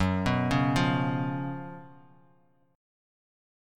GbM7sus4 Chord